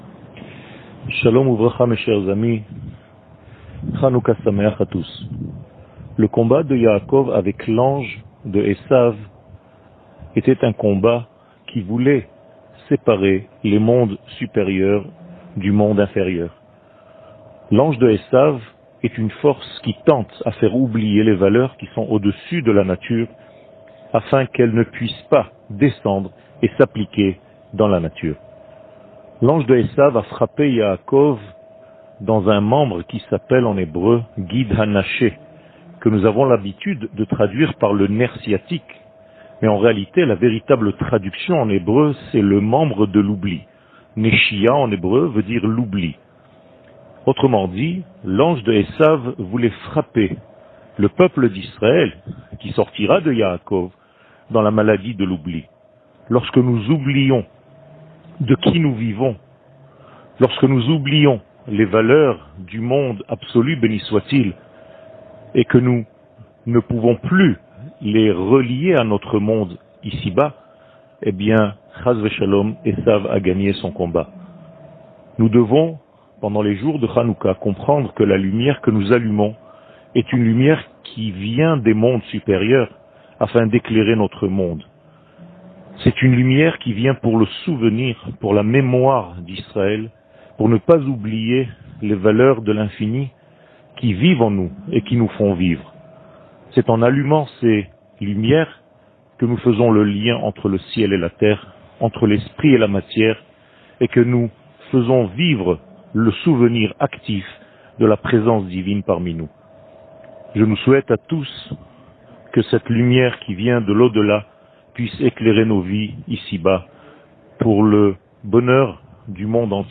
שיעור מ 13 דצמבר 2020
שיעורים קצרים